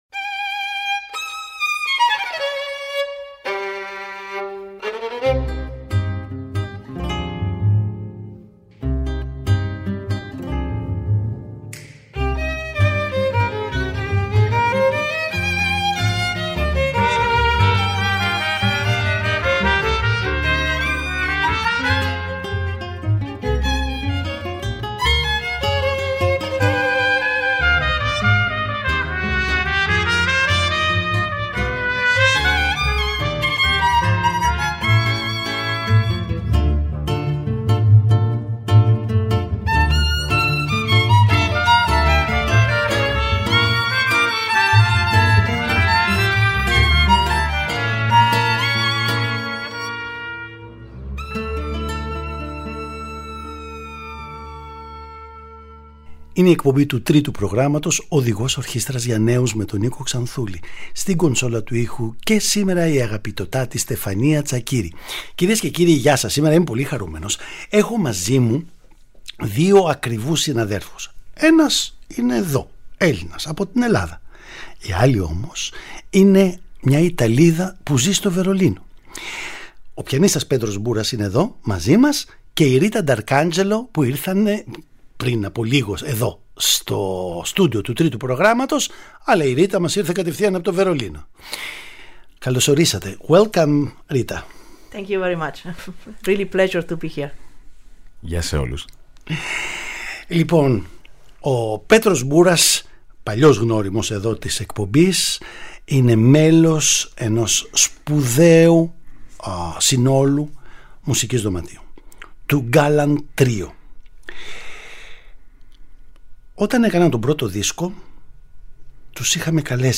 Παραγωγή-Παρουσίαση: Νίκος Ξανθούλης